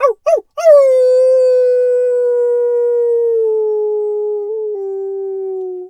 Animal_Impersonations
wolf_2_howl_long_04.wav